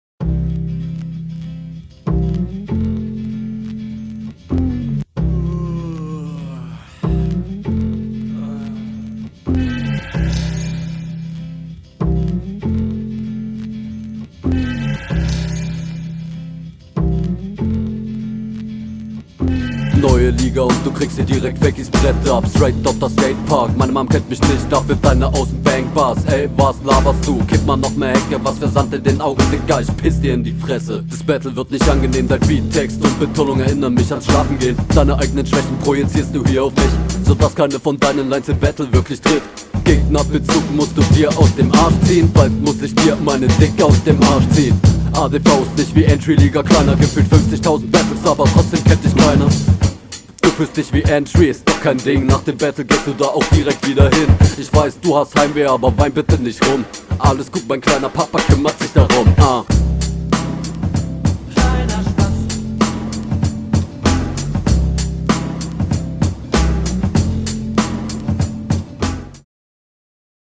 Battle Runden
Deine Flowvarriationen find ich nice, hättest du mehr von einbauen können.
Rein vom Klangbild gefällt mir der Einstieg nicht.